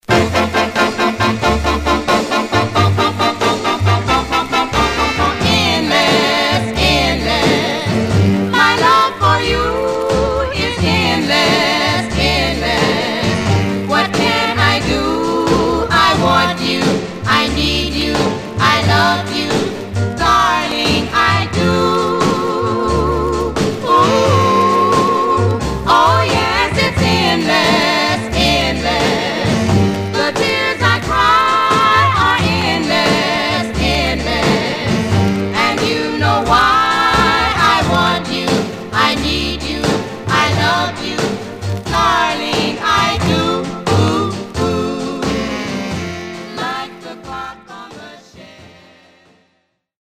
Some surface noise/wear Stereo/mono Mono
Black Female Group